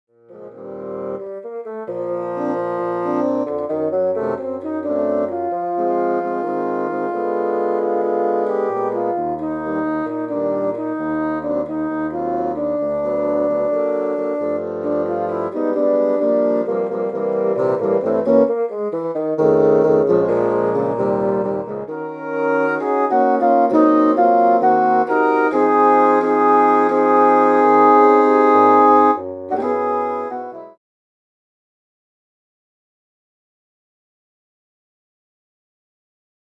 Bassoon Quartet Edition